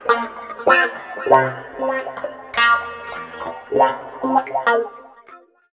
90 GTR 1  -L.wav